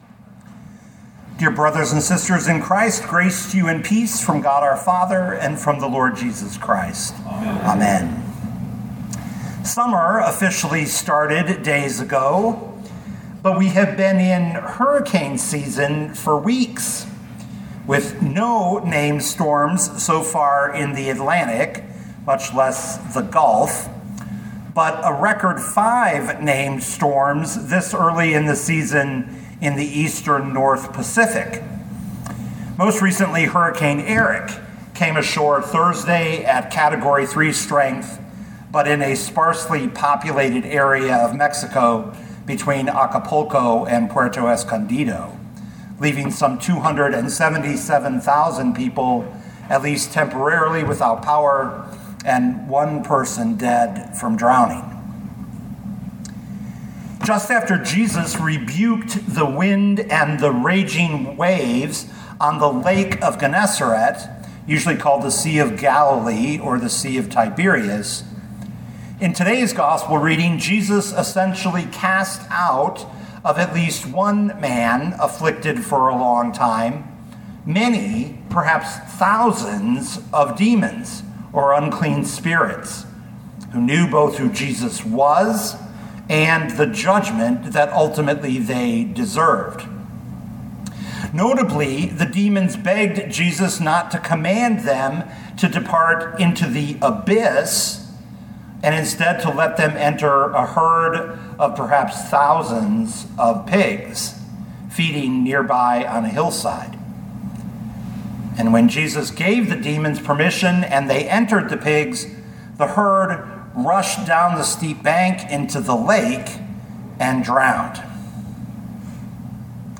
2025 Luke 8:26-39 Listen to the sermon with the player below, or, download the audio.